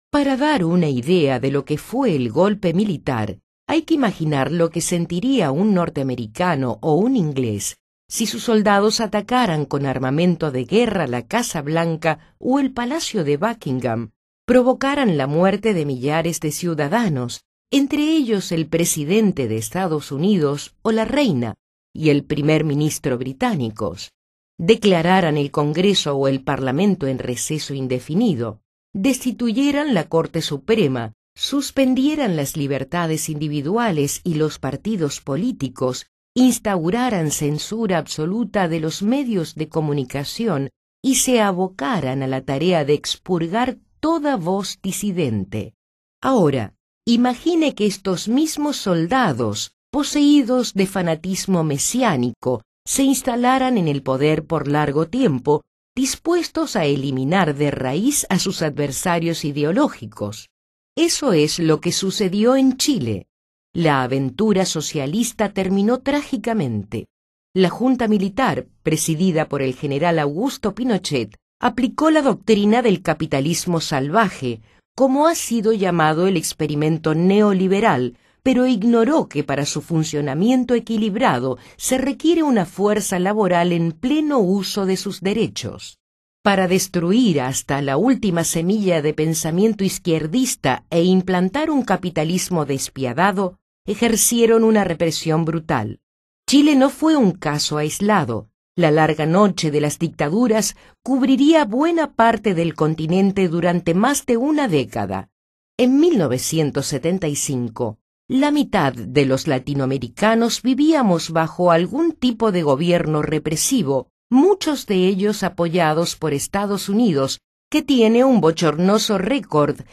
Lectura+De+Isabel+Allende+Sobre+El+Golpe+Militar+En+Chile (audio/mpeg)